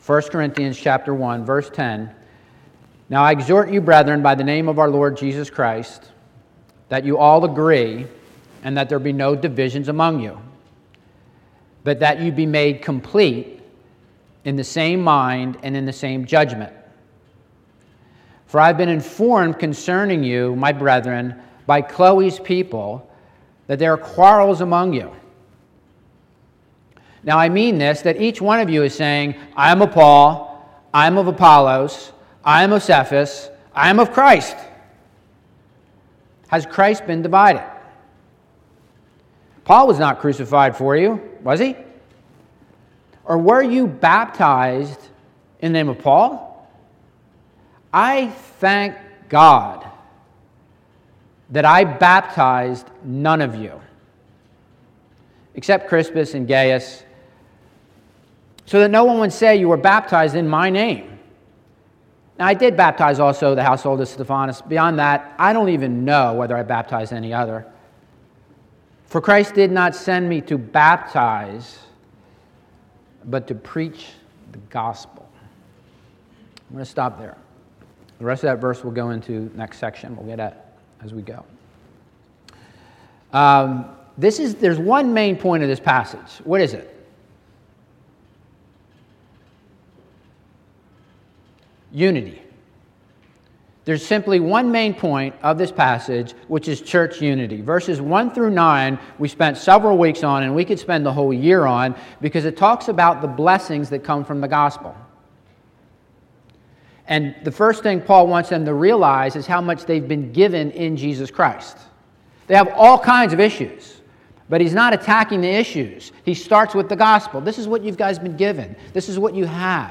Bible Text: 1 Corinthians 1:10-17 | Preacher